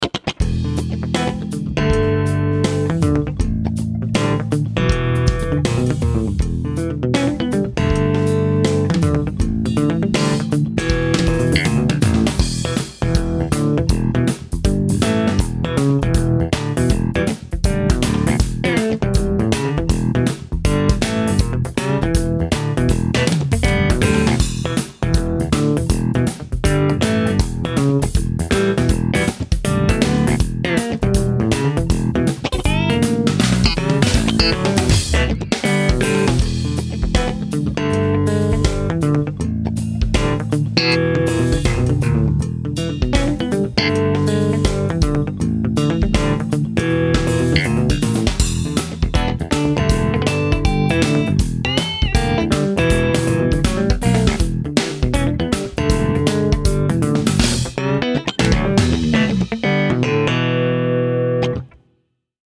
Funky01_Clean.mp3